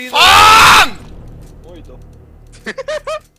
Worms speechbanks
YoullRegretThat.WAV